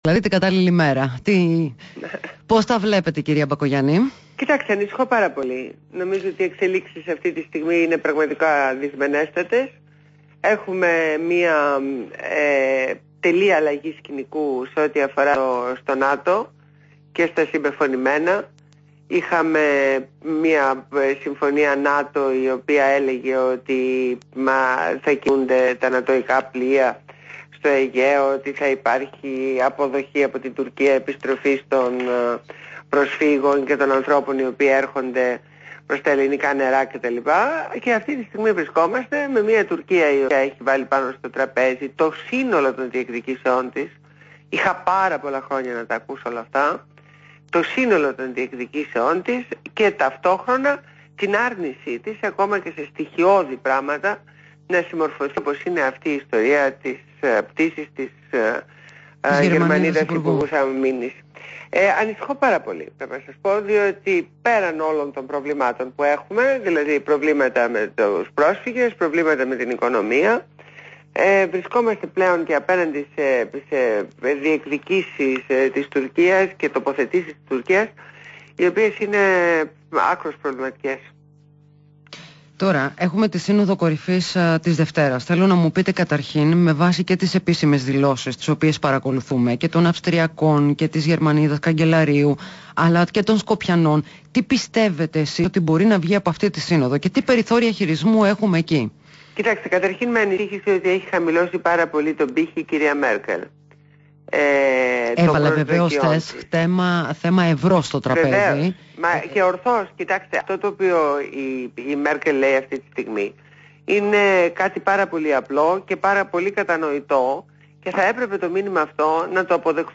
Συνέντευξη στο ραδιόφωνο του REALfm